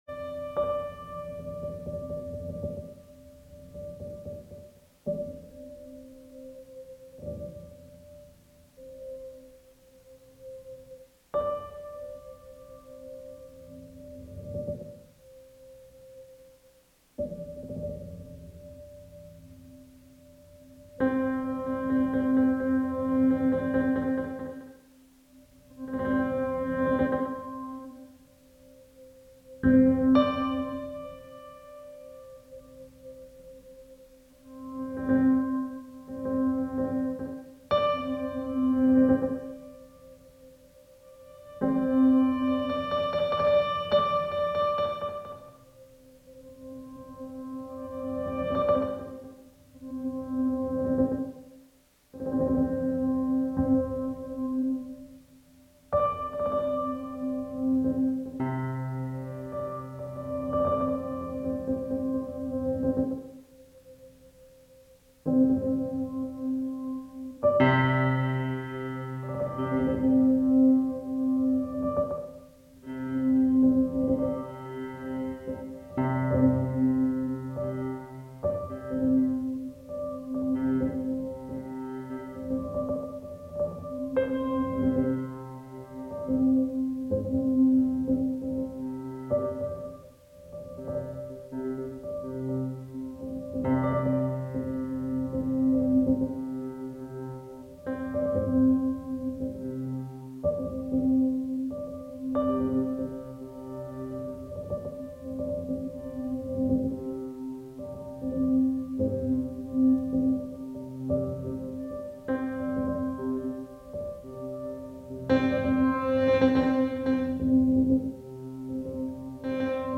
A hidden Bluetooth speaker amplifies an original piano composition, creating an immersive experience.
piano.mp3